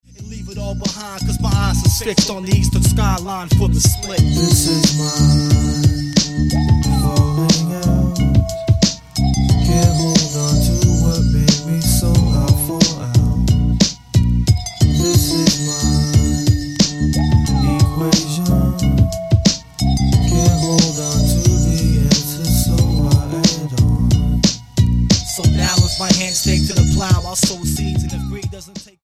STYLE: Hip-Hop
spacey synths and heavy bass line